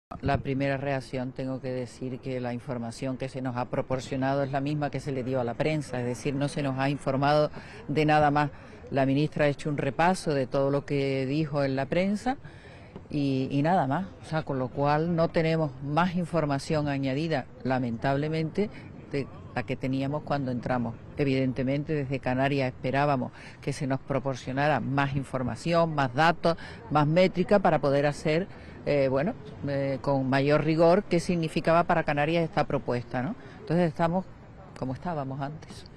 Declaraciones de la consejera de Hacienda y Relaciones con la Unión Europea, Matilde Asián: